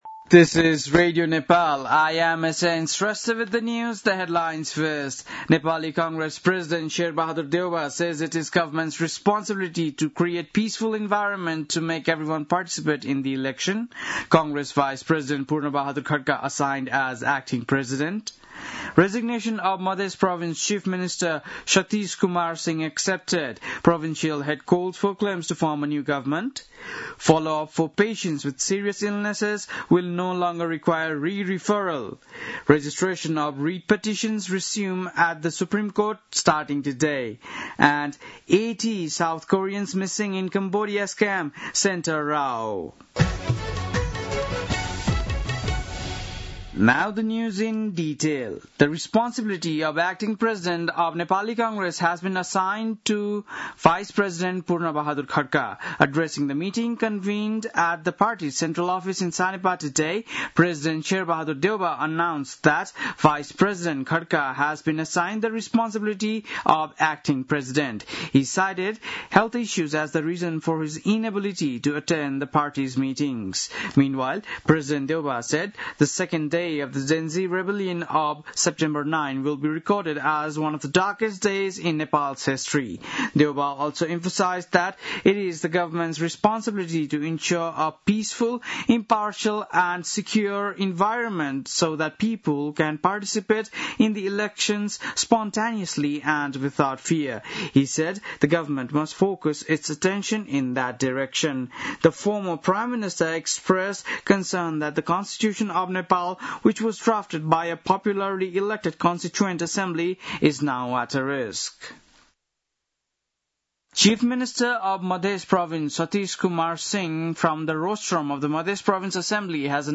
बेलुकी ८ बजेको अङ्ग्रेजी समाचार : २८ असोज , २०८२